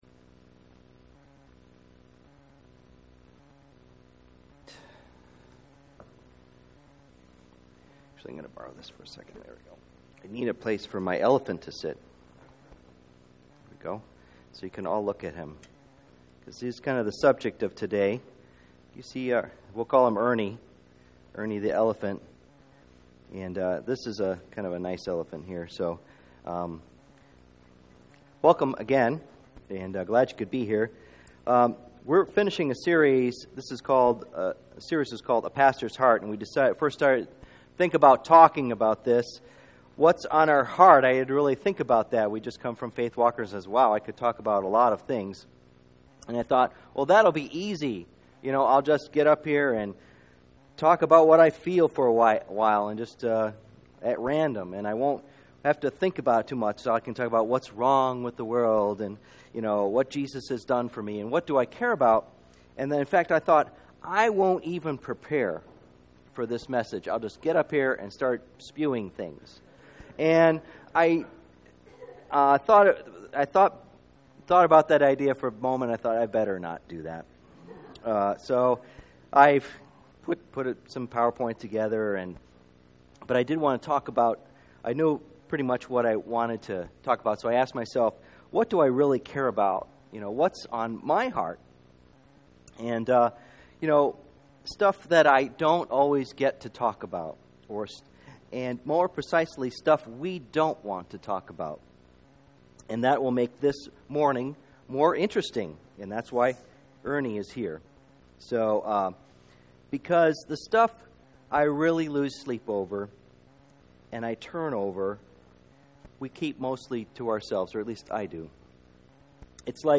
Pastor's Heart Series Service Type: Sunday Morning %todo_render% « Pastor’s Heart for the Church